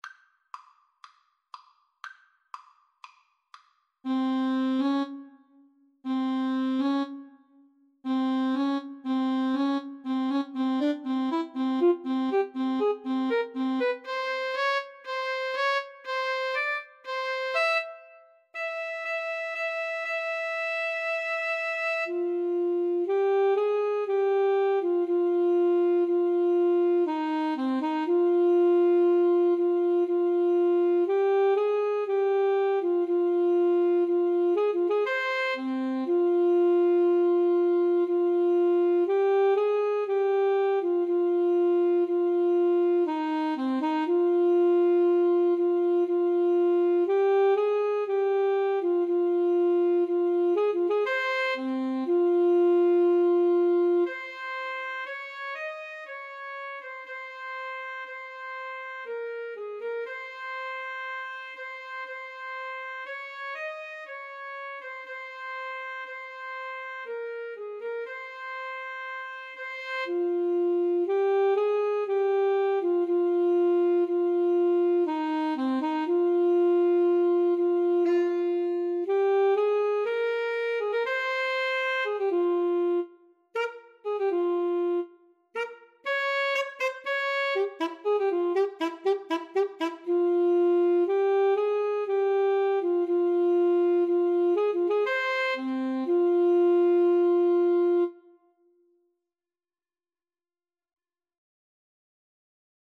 F minor (Sounding Pitch) C minor (French Horn in F) (View more F minor Music for Alto Saxophone Duet )
Allegro con fuoco (View more music marked Allegro)
Alto Saxophone Duet  (View more Easy Alto Saxophone Duet Music)
Classical (View more Classical Alto Saxophone Duet Music)